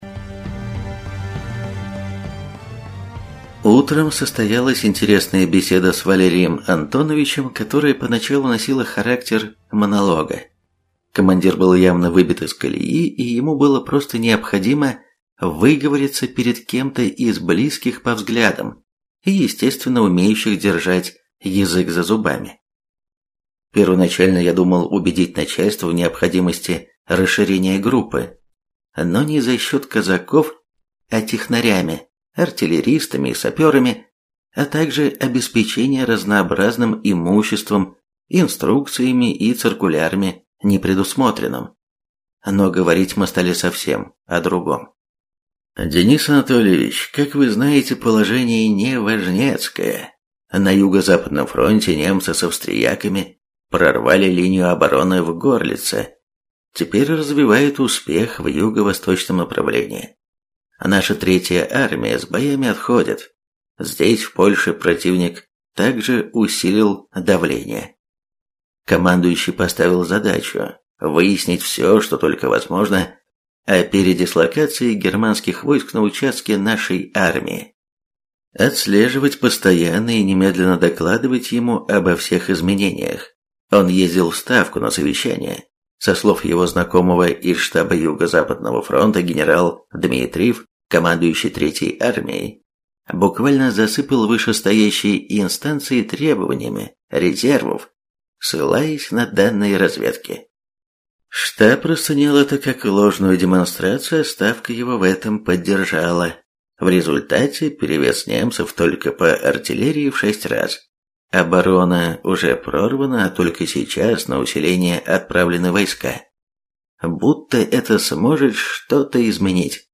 Аудиокнига Бешеный прапорщик | Библиотека аудиокниг
Прослушать и бесплатно скачать фрагмент аудиокниги